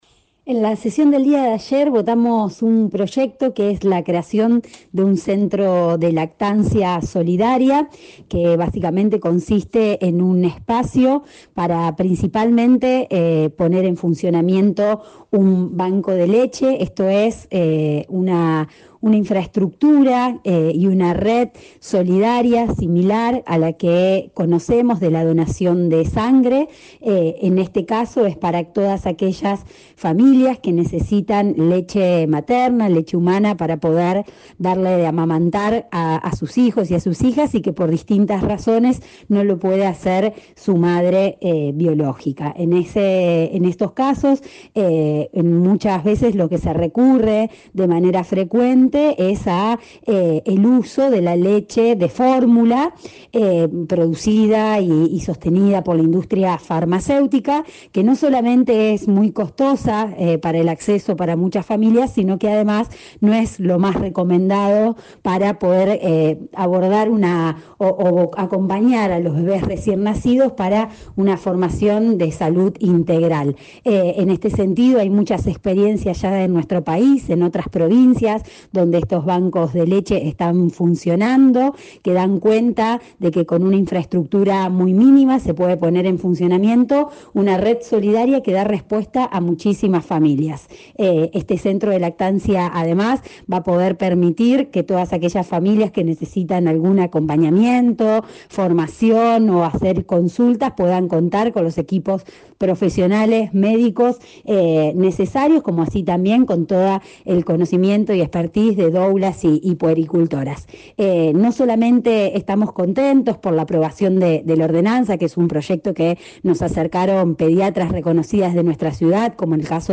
“En estos casos de manera frecuente se recurre al uso de la leche de fórmula de la industria farmacéutica que no solamente es muy costosa sino que no es lo más adecuado para acompañar a bebes recién nacidos para una salud integral” expresó a Primera Plana de Cadena 3 Rosario la legisladora municipal.